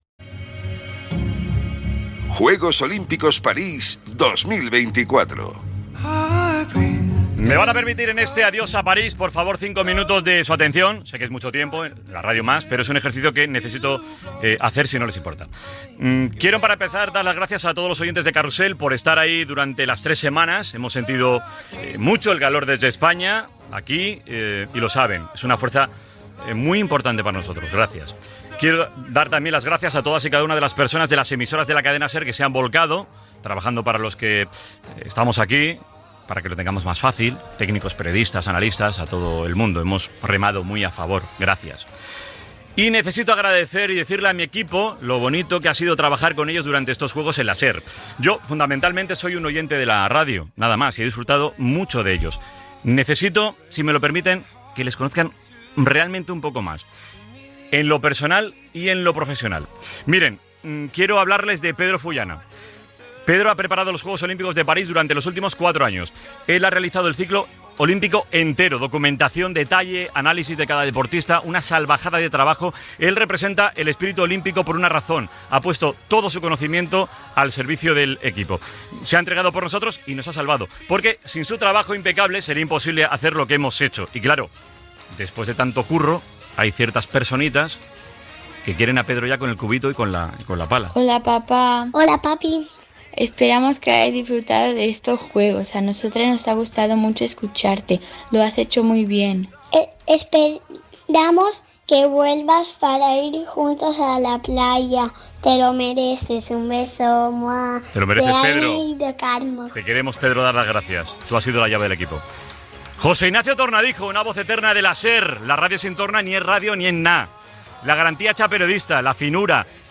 Agraïment a l'equip del programa en l'últim espai fet des de París, amb motiu dels Jocs Olímpics de 2024, amb intervencions breus de familiars seus Gènere radiofònic Esportiu